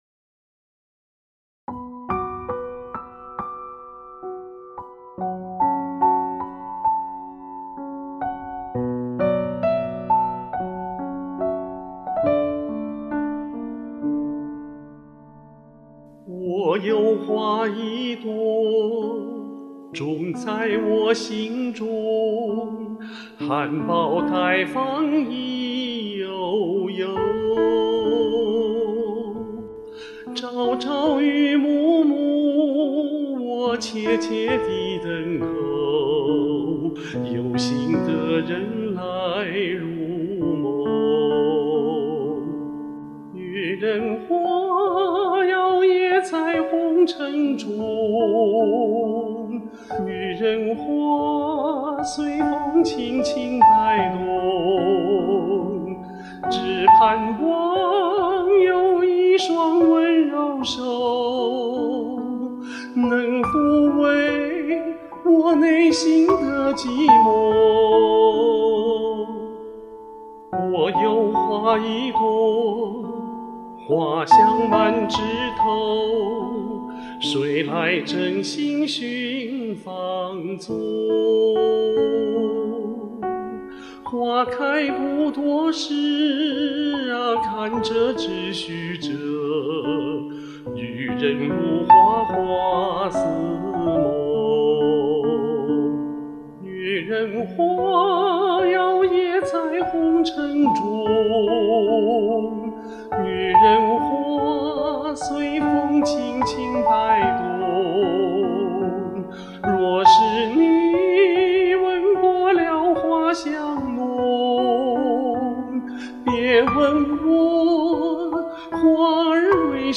美丽动情的歌声，委婉细腻，动人心弦！
虚虚实实，假声无敌！
细腻婉约，温柔深情，两版都好听！